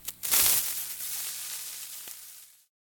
fuse.ogg